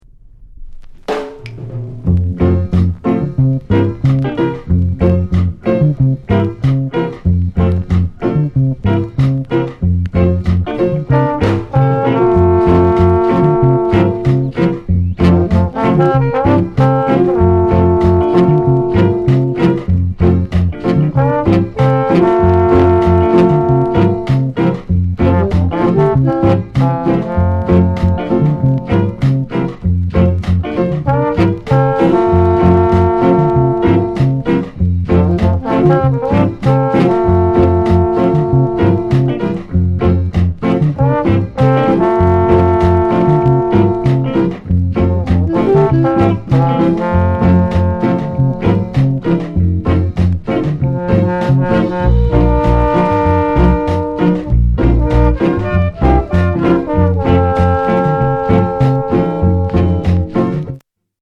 ROCKSTEADY INST